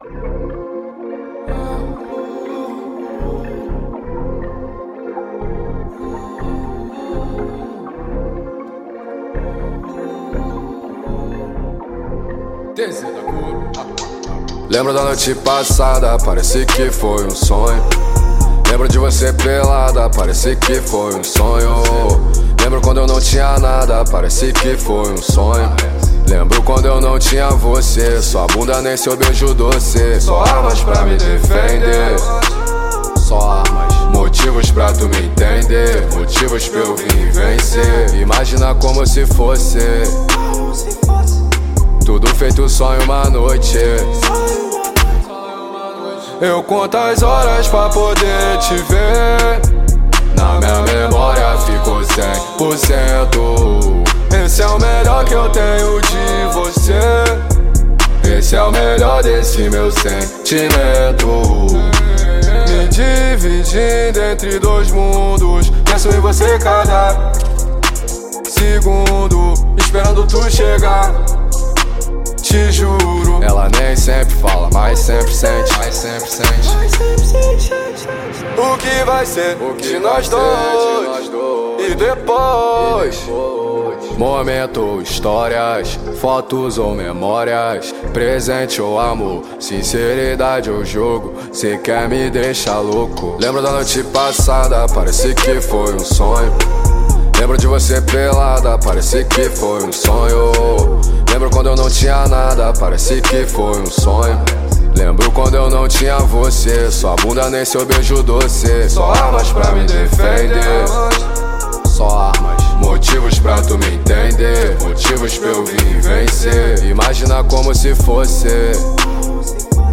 2025-03-19 01:42:25 Gênero: Trap Views